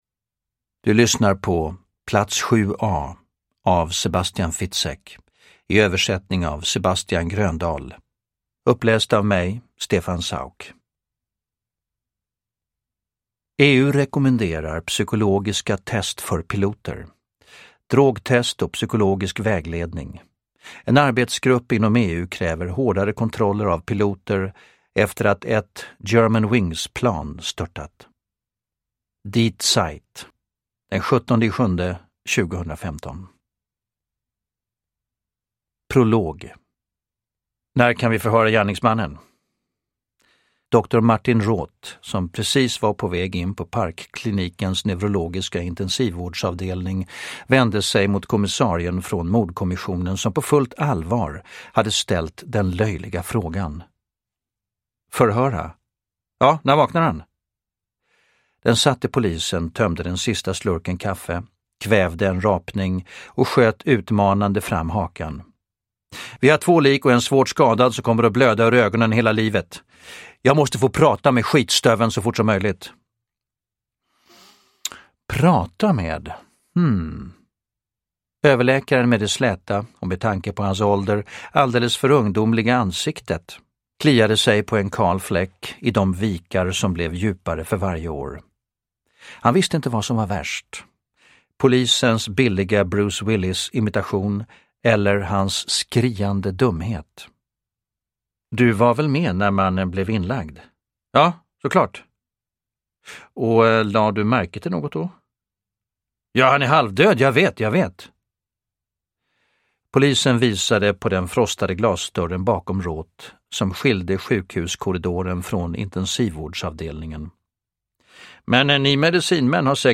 Plats 7A – Ljudbok – Laddas ner
Uppläsare: Stefan Sauk